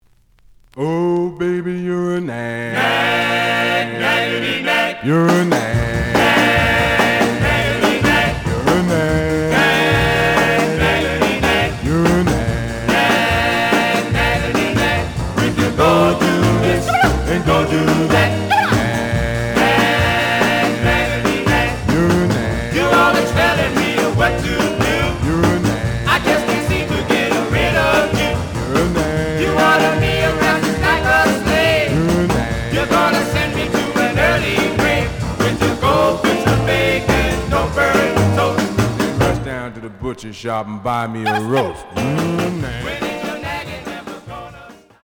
The audio sample is recorded from the actual item.
●Genre: Rhythm And Blues / Rock 'n' Roll